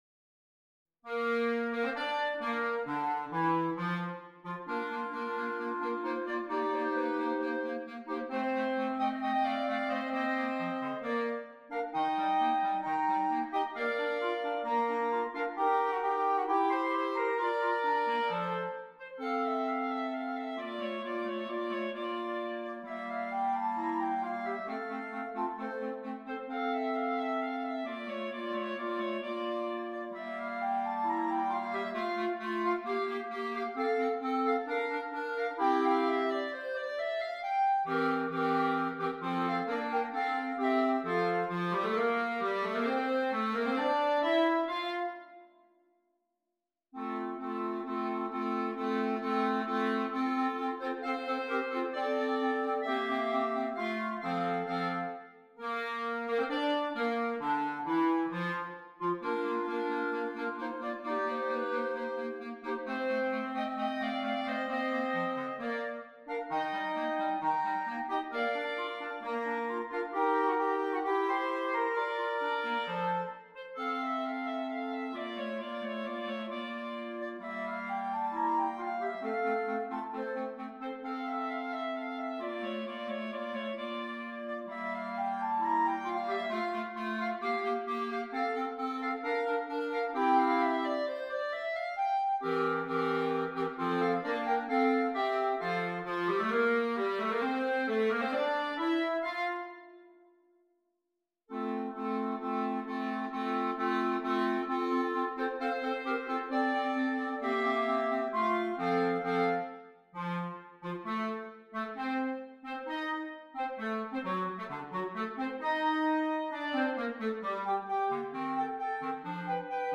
4 Clarinets